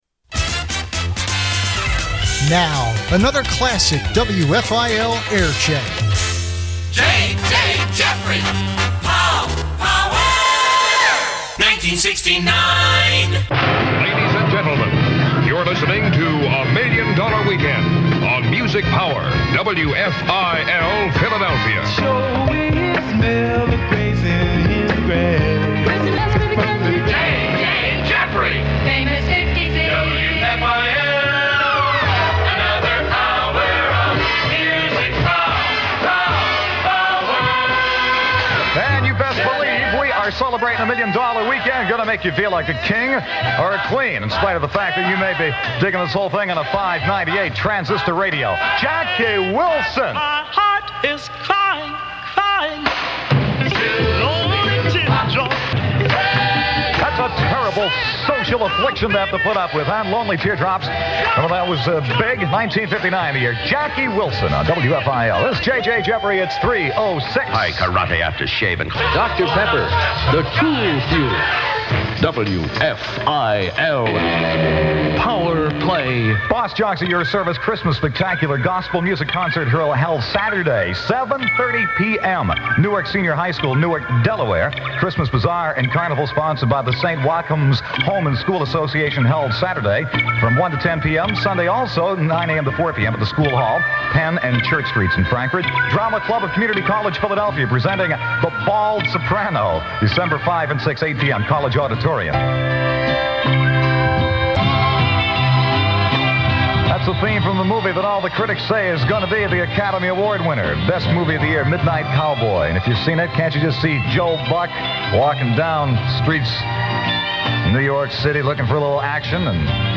This Week's Aircheck